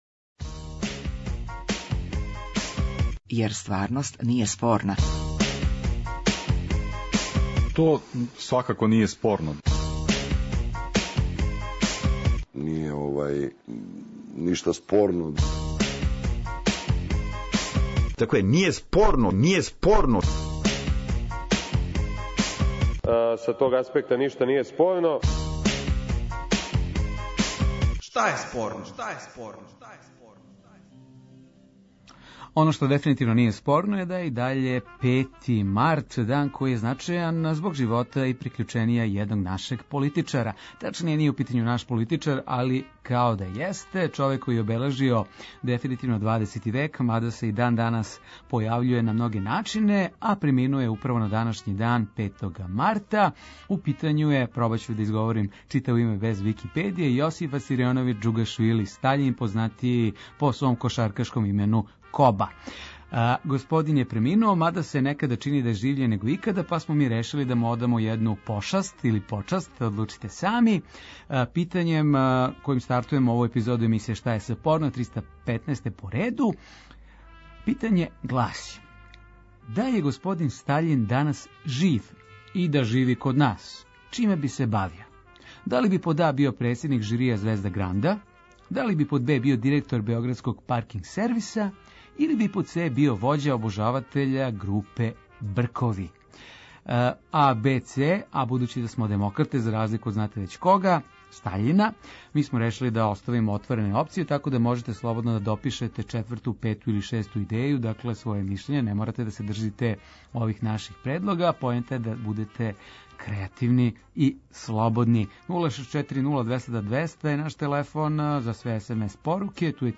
Радијски актуелно - забавни кабаре интерактивног карактера